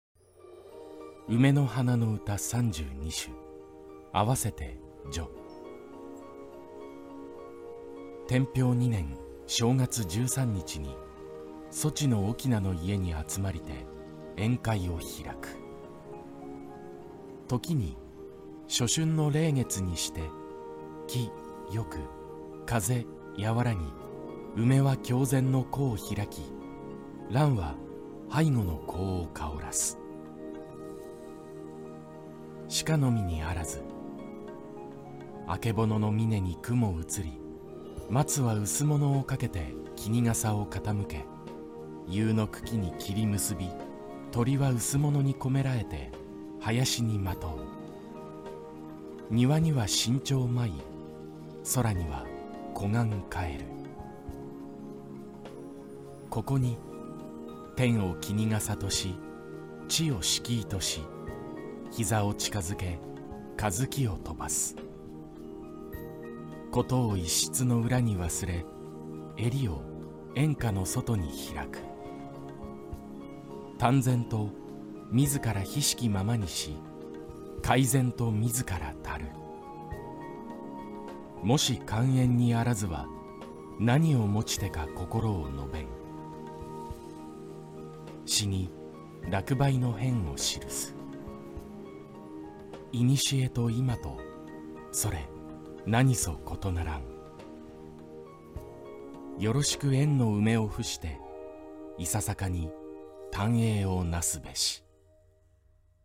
朗読をどこかからか拝借